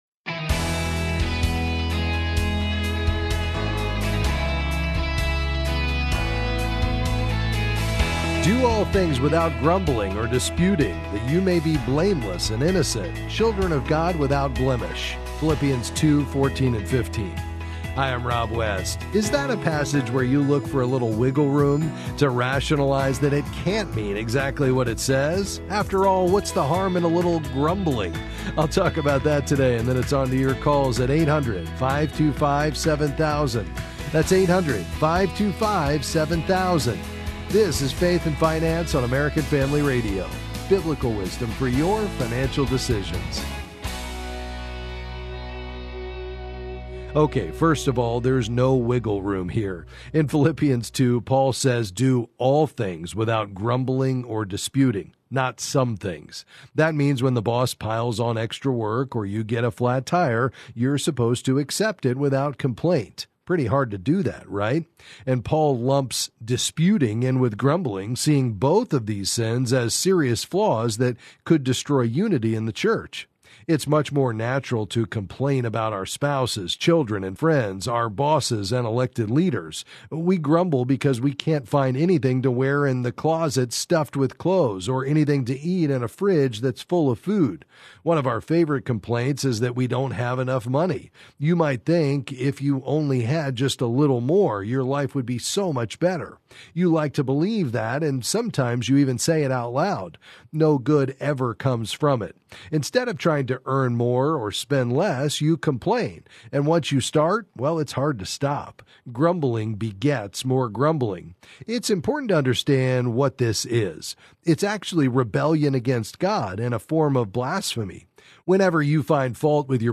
Then he’ll take your calls and answer the financial questions on your mind.